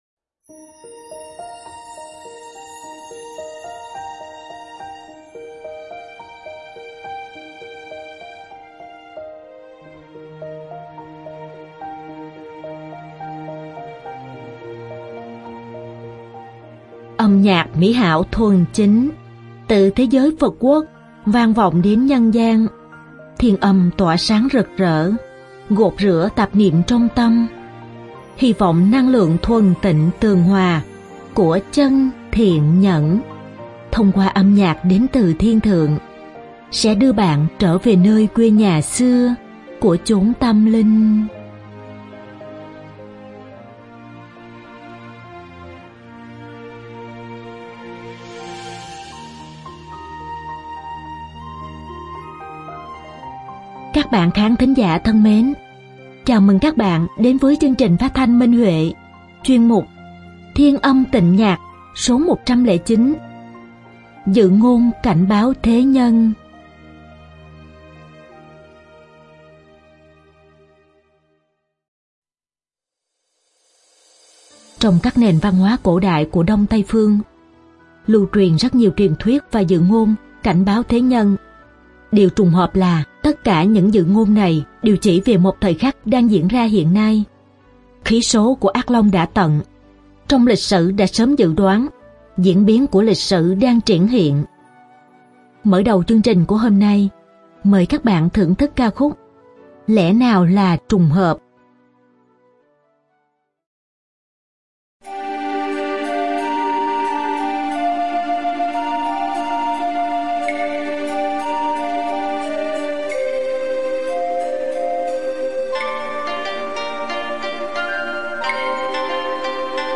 Các bạn khán thính giả thân mến, chào mừng các bạn đến với chương trình phát thanh Minh Huệ, chuyên mục “Thiên Âm Tịnh Nhạc” Số 109: Dự ngôn cảnh báo thế nhân.